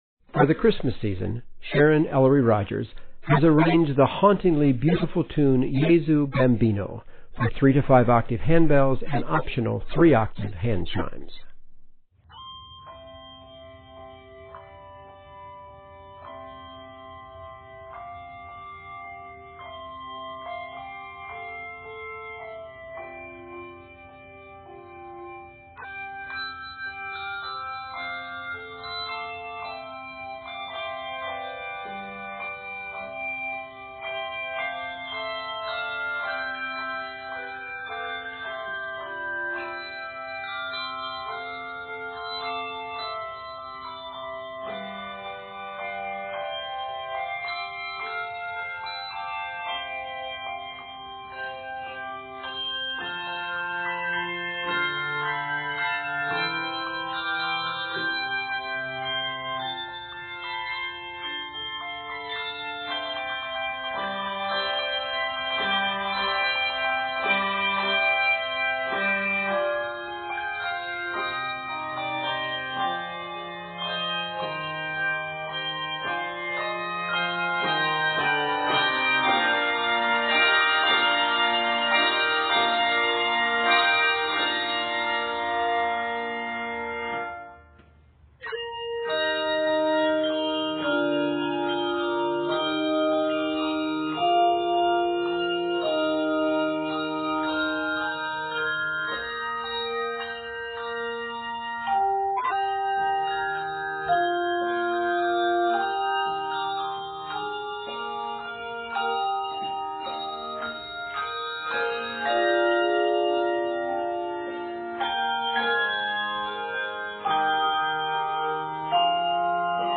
hauntingly beautiful tune